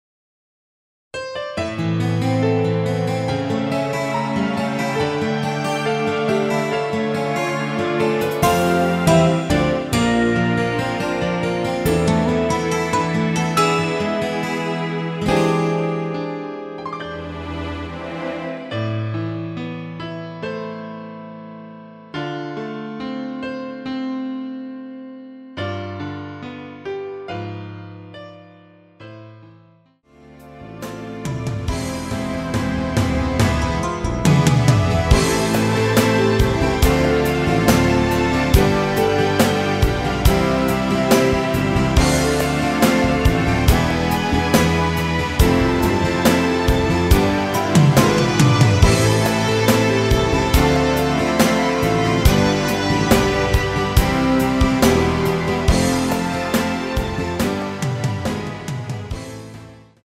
Am
앞부분30초, 뒷부분30초씩 편집해서 올려 드리고 있습니다.
중간에 음이 끈어지고 다시 나오는 이유는